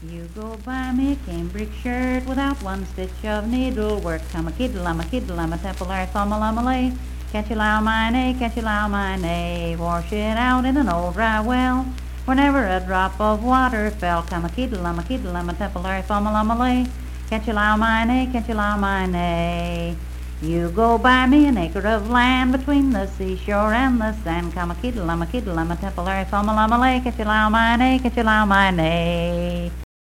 Unaccompanied vocal music
Verse-refrain 3(4w/R).
Performed in Coalfax, Marion County, WV.
Dance, Game, and Party Songs
Voice (sung)